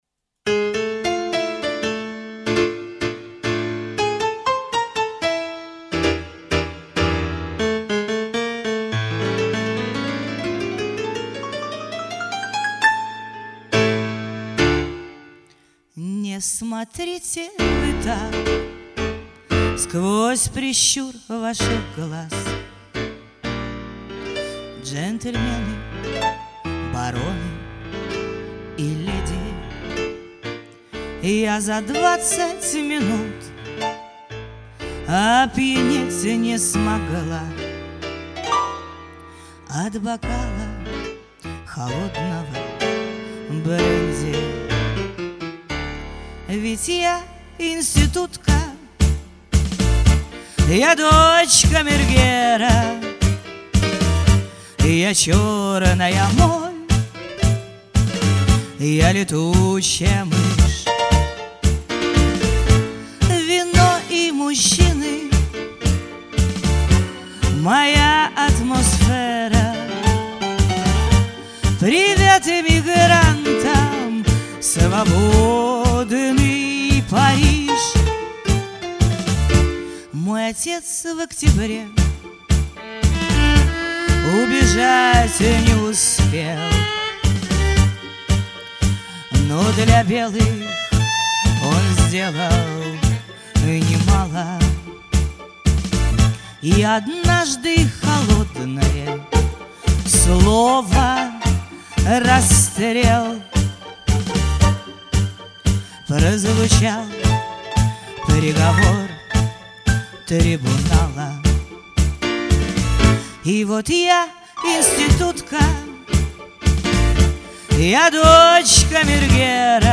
Атмосфэра превосходная получилась- окунулся в салонную жизнь